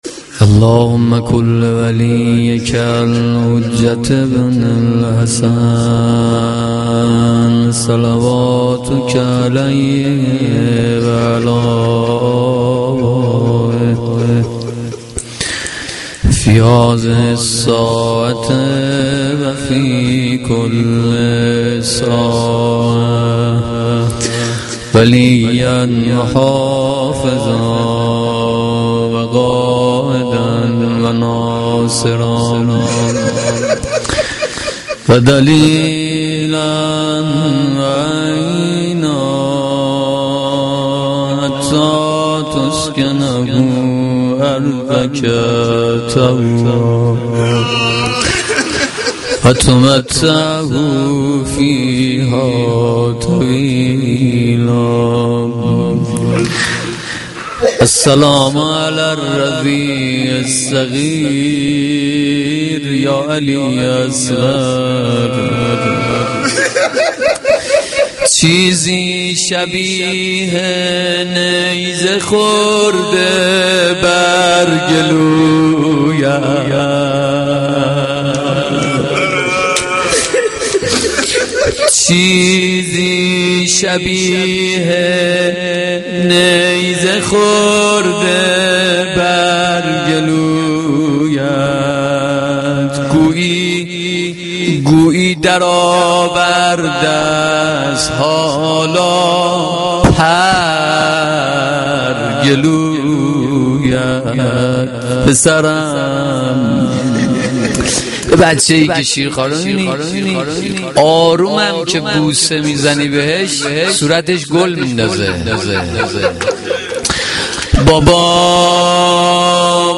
مداحی
Shab-7-Moharam-2.mp3